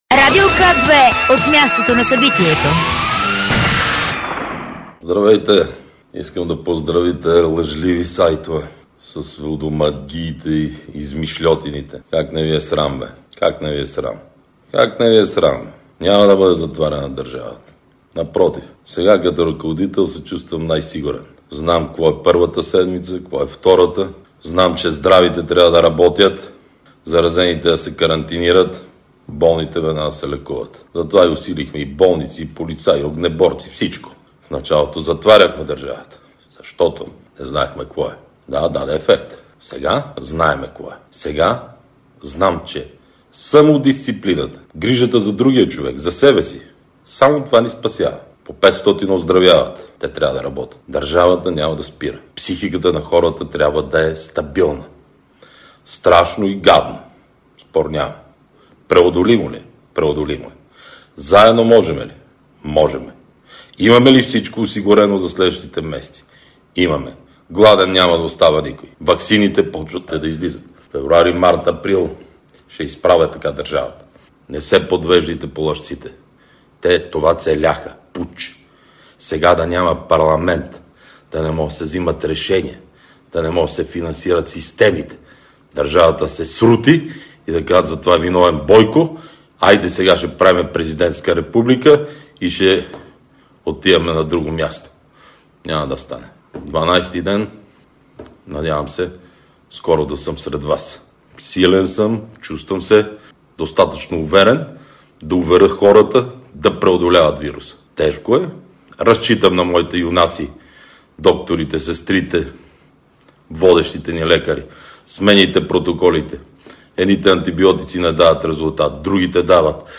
14.15 - Заседание на Прокурорската колегия на Висшия съдебен съвет. директно от мястото на събитието (заседателната зала в сградата на Висшия съдебен съвет, ул. „Eкзарх Йосиф" № 12)
Директно от мястото на събитието